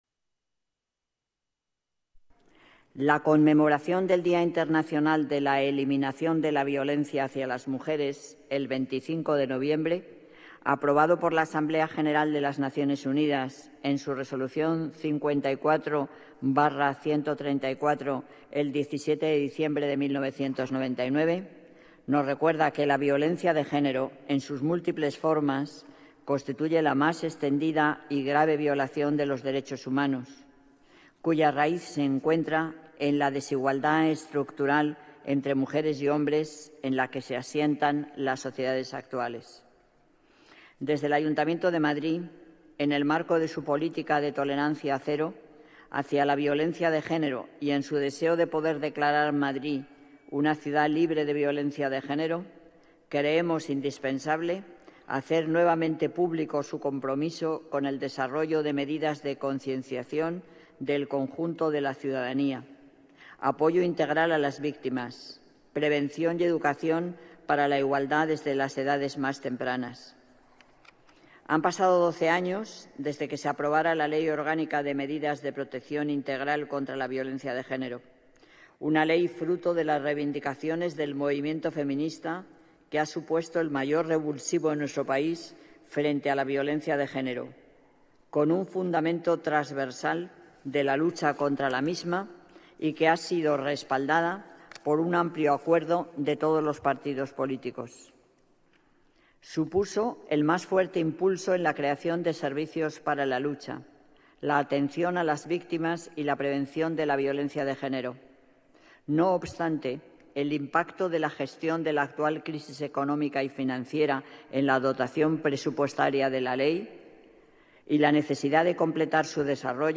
Ha sido aprobada por unanimidad en la sesión extraordinaria del Pleno del Ayuntamiento de Madrid celebrada en la mañana de hoy, viernes 25 de noviembre